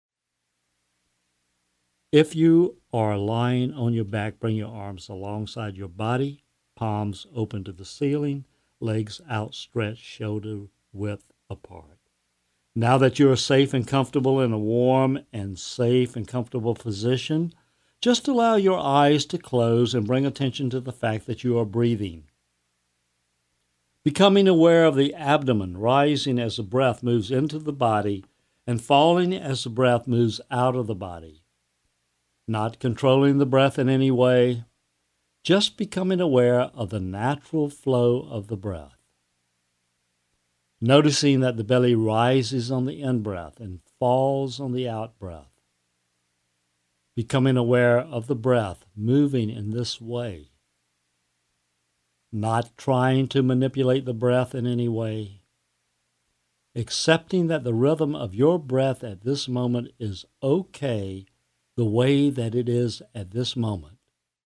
Body Scan Guided Meditation